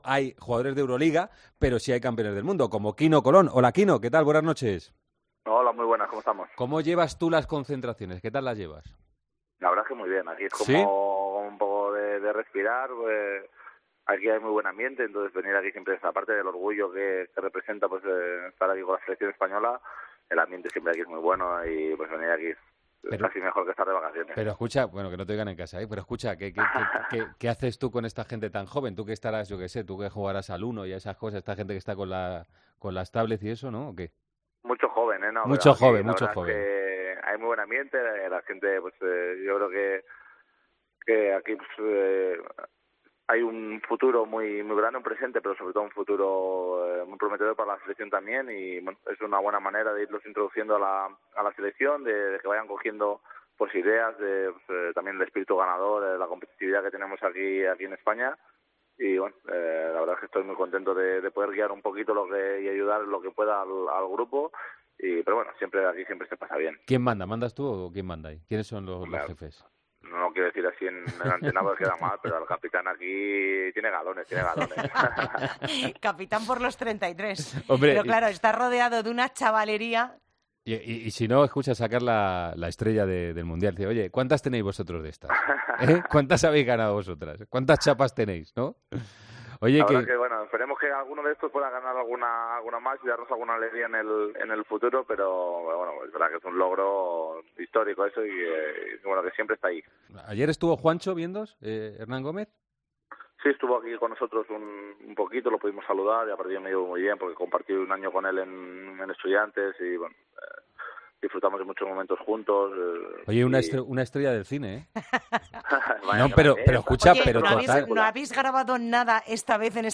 AUDIO: Hablamos con el jugador de la selección española, uno de los pilares de las ventanas, sobre el Europeo que jugará el equipo en septiembre.